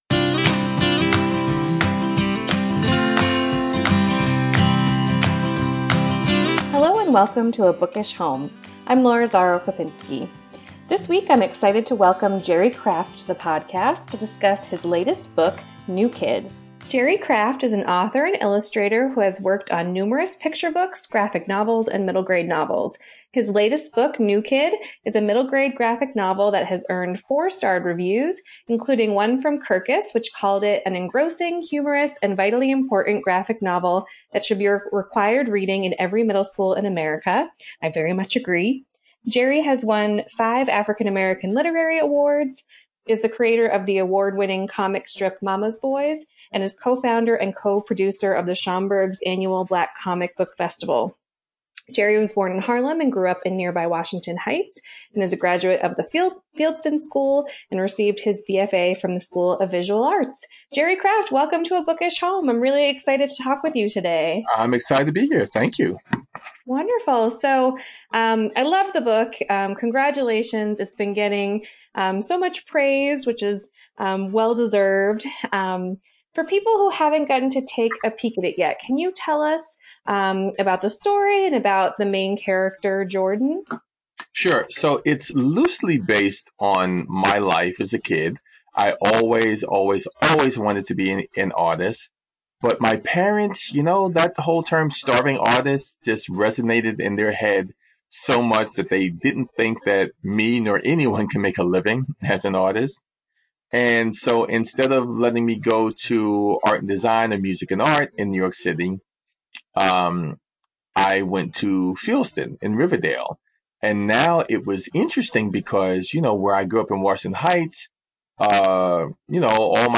This week I’m excited to welcome Jerry Craft to the podcast to discuss his latest book, New Kid.